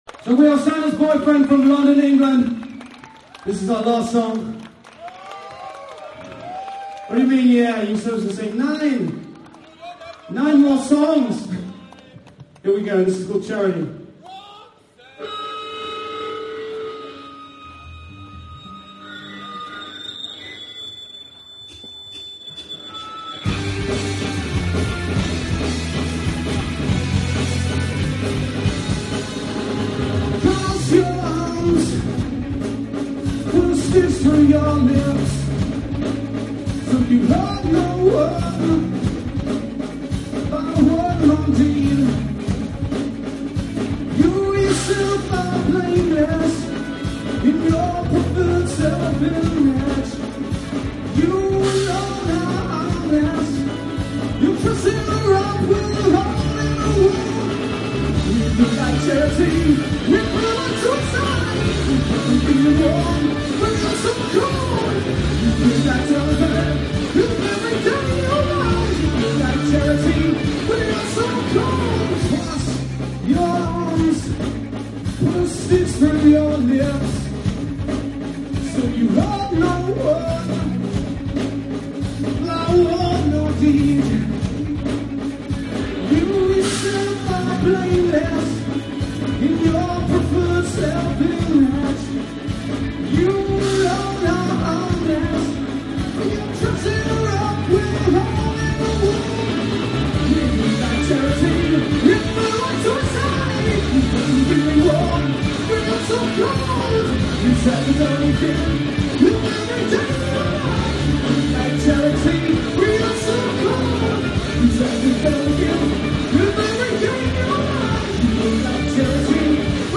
Aug. 11,2001 HALDERN
bass guitar